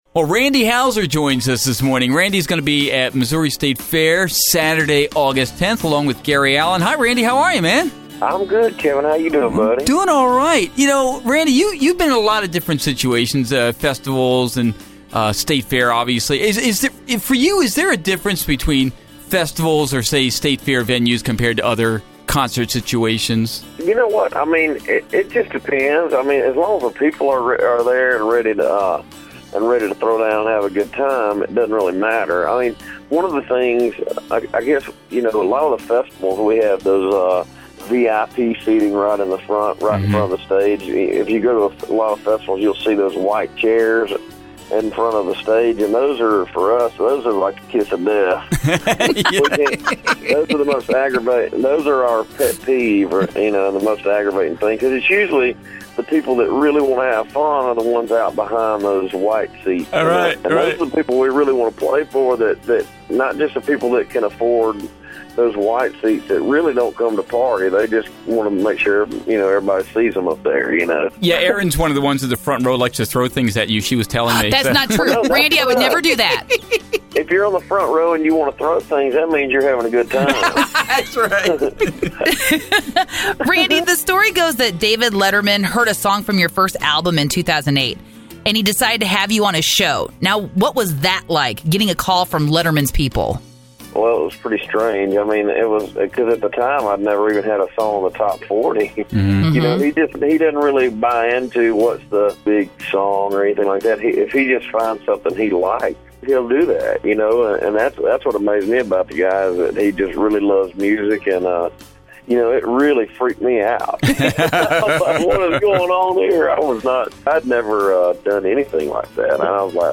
Randy Houser interview 7/12/13